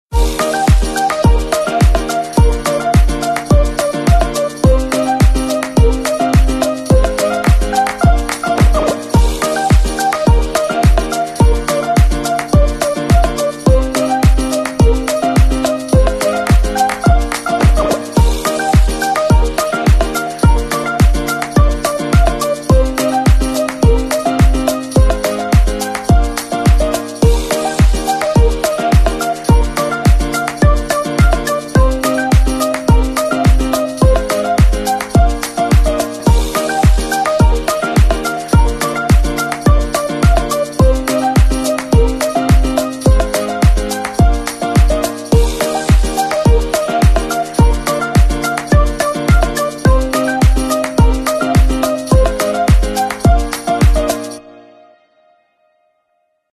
Oven Samono Sound Effects Free Download